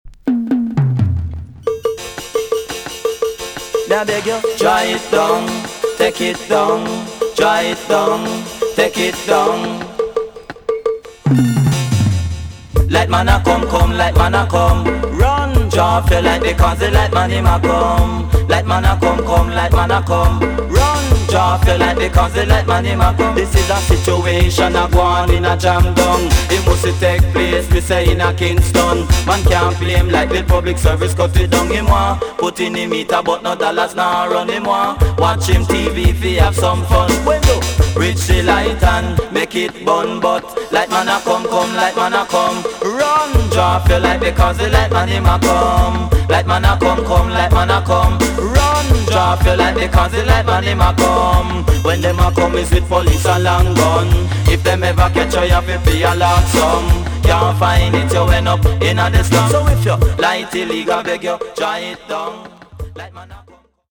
TOP >80'S 90'S DANCEHALL
EX 音はキレイです。